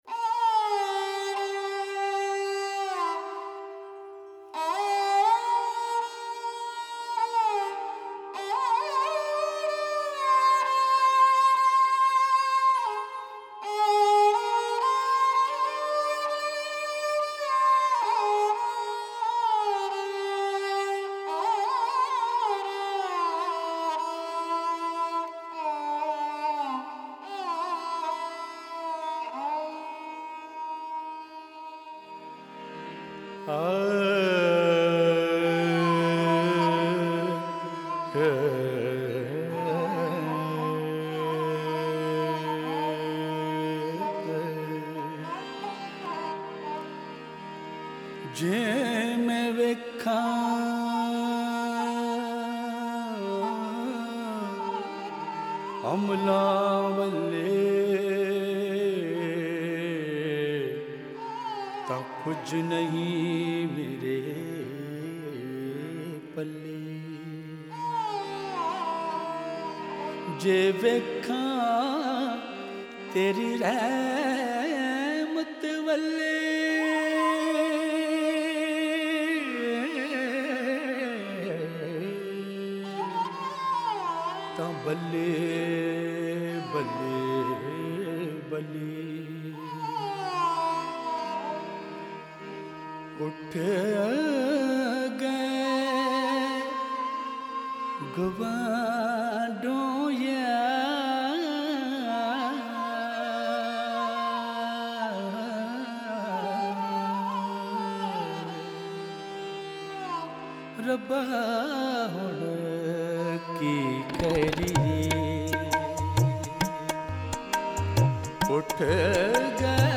Punjabi Kalam